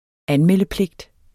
Udtale [ ˈanˌmεlˀə- ]